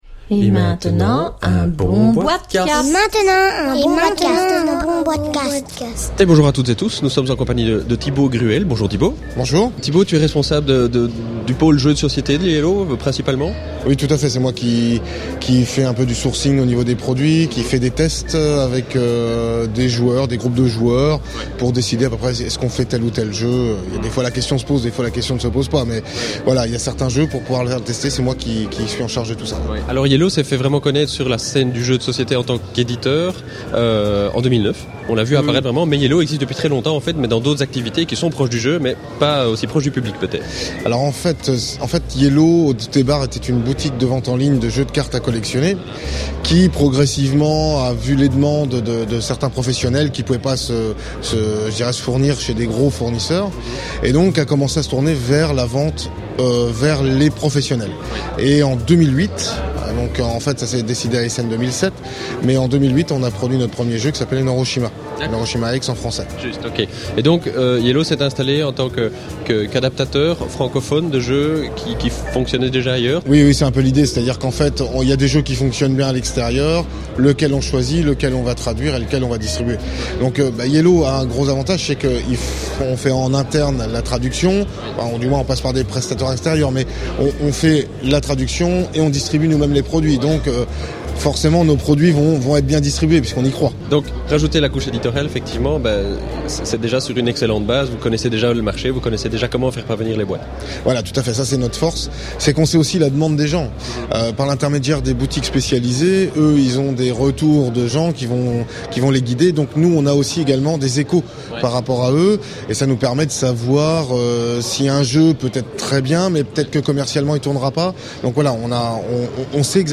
(enregistré lors du salon international de la Nuremberg Toy Fair 2010)